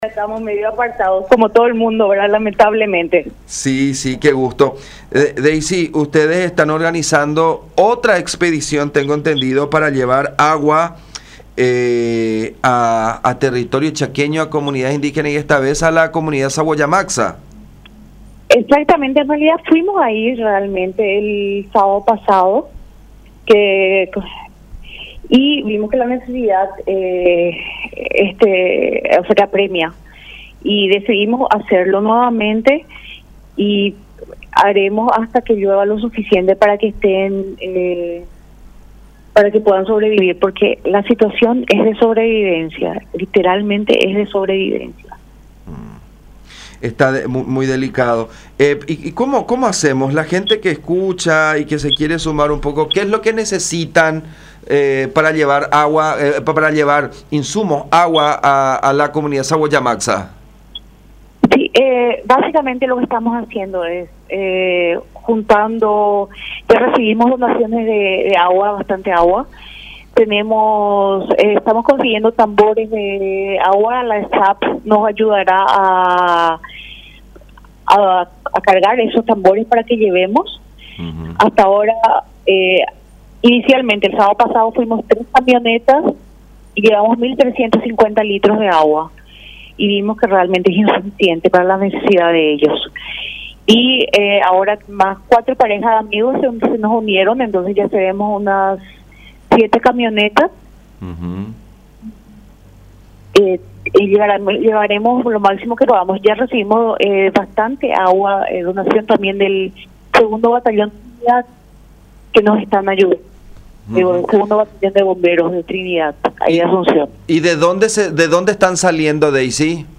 en charla con La Unión.